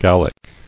[Gal·lic]